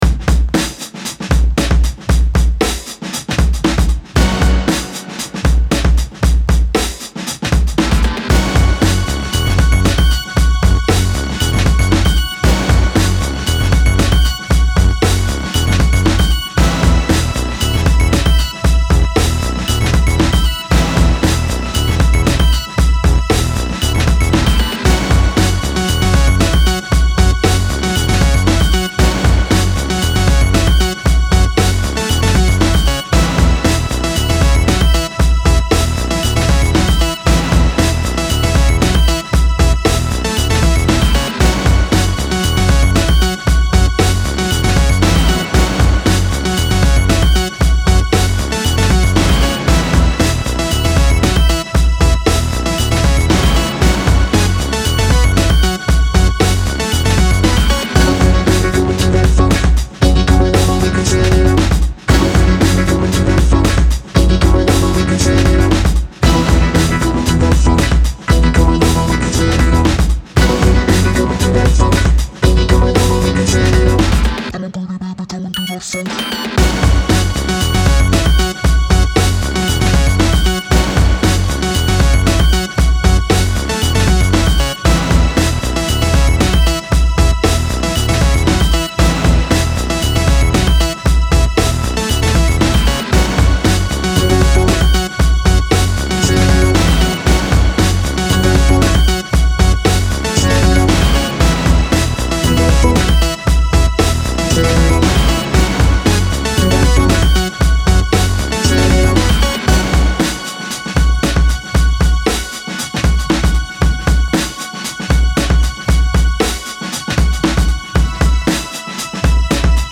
Style Style EDM/Electronic, Hip-Hop
Mood Mood Cool, Driving
Featured Featured Bass, Drums, Synth +1 more
BPM BPM 116